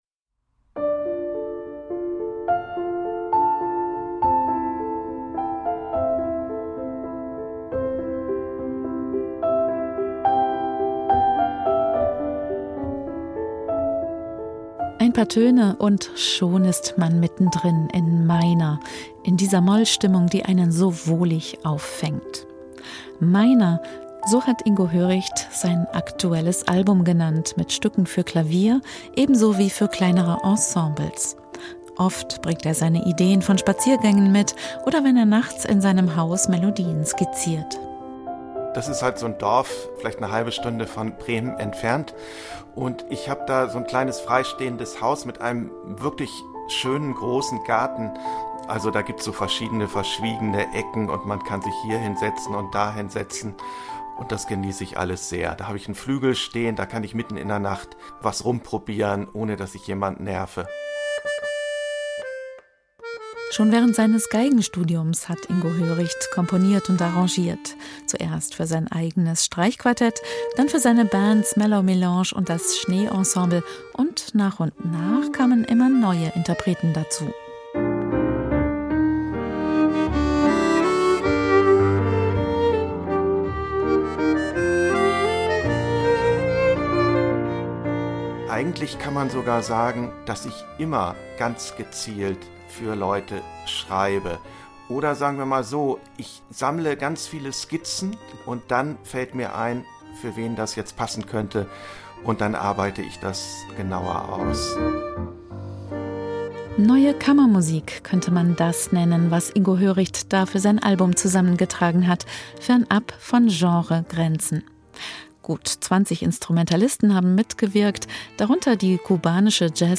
] Moll mit Sogwirkung